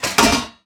metal_impact_light_05.wav